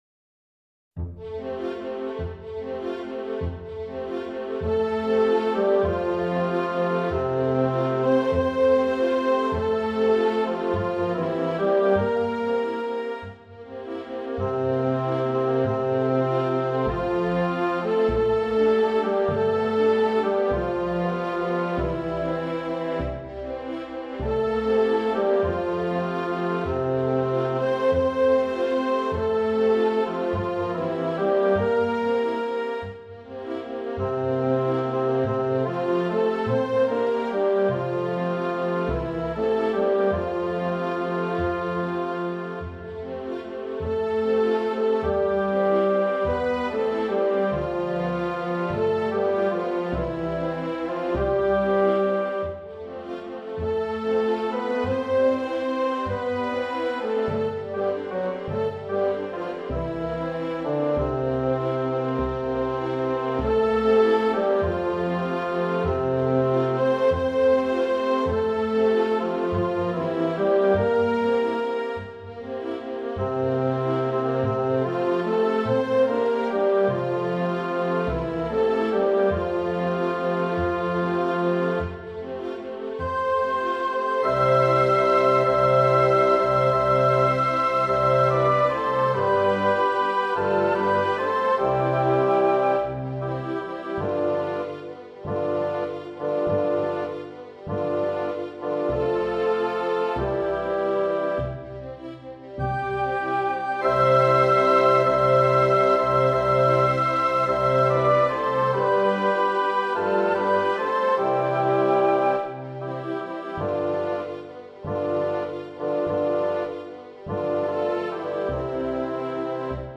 Lo proponiamo in versione didattica per flauto.